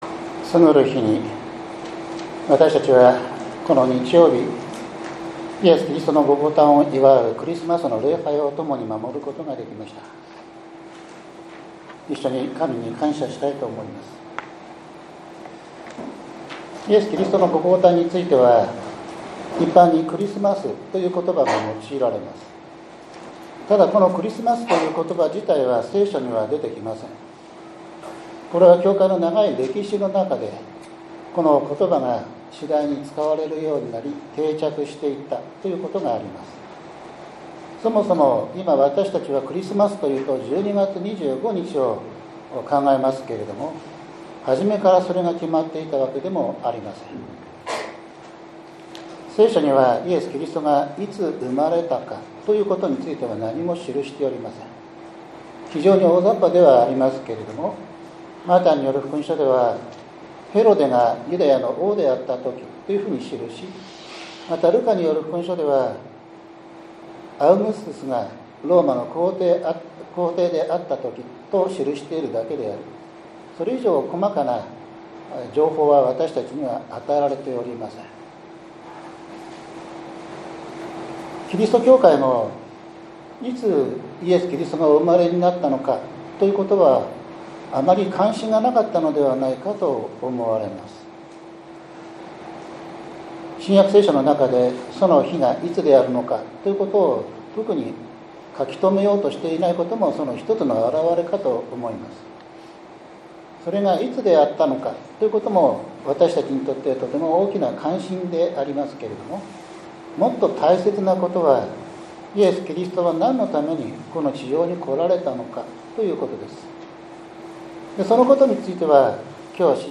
１２月２９日（日）主日礼拝 イザヤ書４０章１節～２節 ガラテヤ４章４節～７節